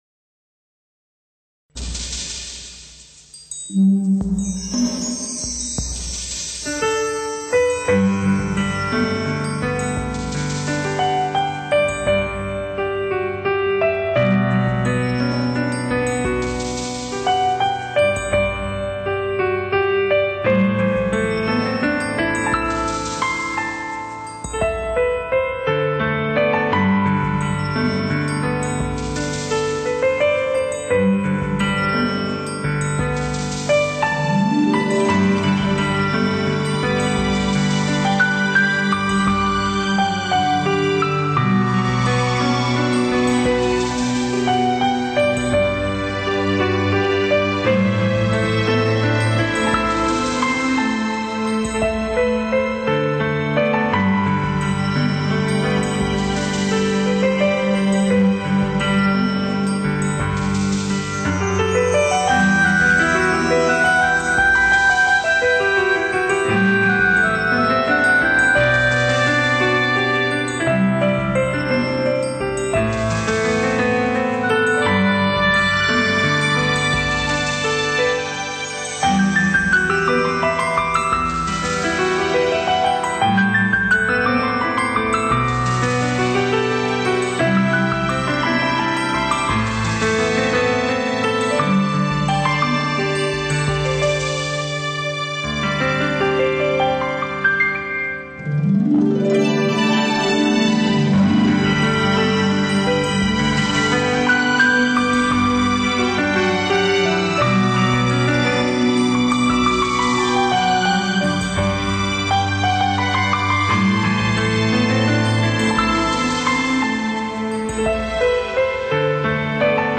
【钢琴】